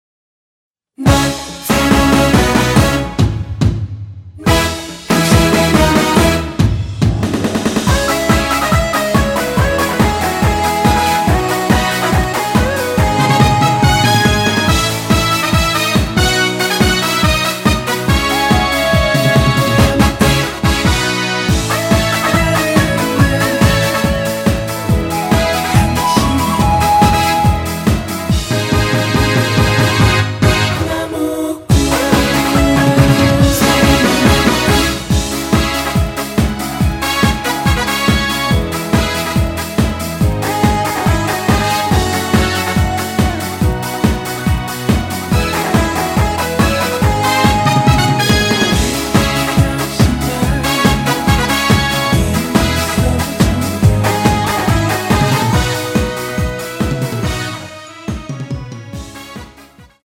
C#m
앞부분30초, 뒷부분30초씩 편집해서 올려 드리고 있습니다.
중간에 음이 끈어지고 다시 나오는 이유는